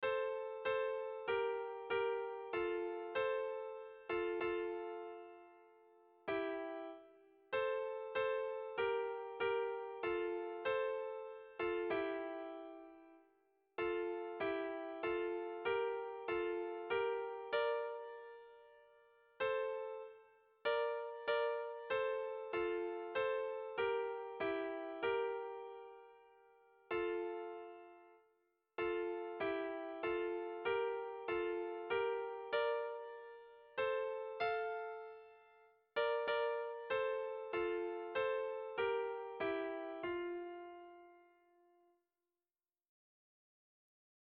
Erlijiozkoa
Araba < Basque Country
Lauko txikia (hg) / Bi puntuko txikia (ip)